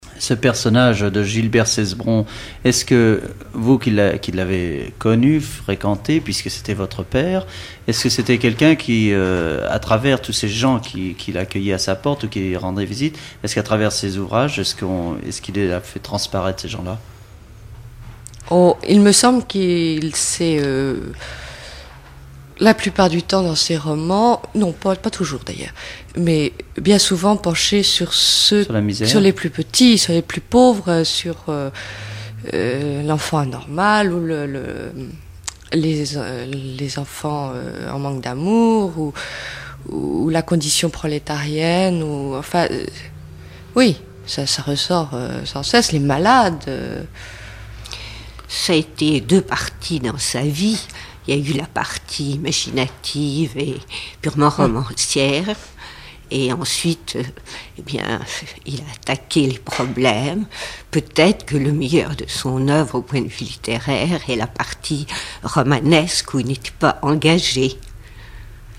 numérisation d'émissions par EthnoDoc
Témoignage